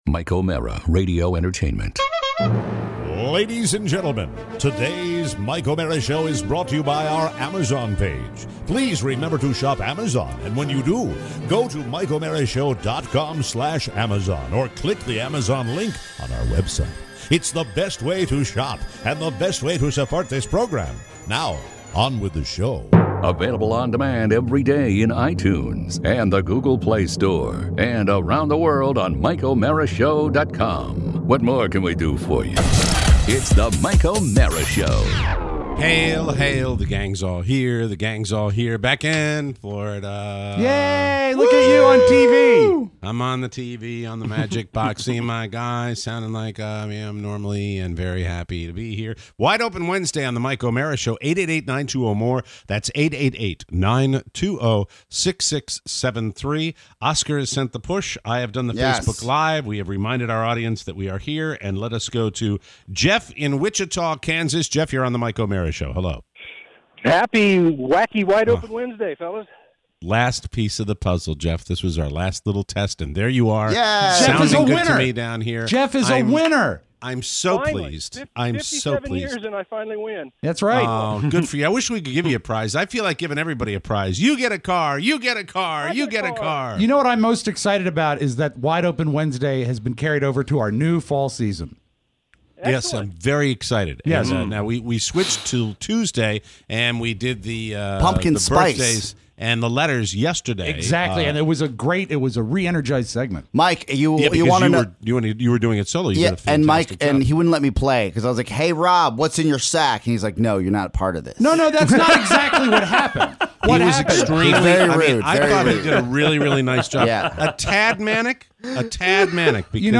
Your calls… including a live report from San Juan.